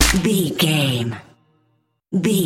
Aeolian/Minor
drum machine
synthesiser
hip hop
Funk
neo soul
energetic
bouncy
funky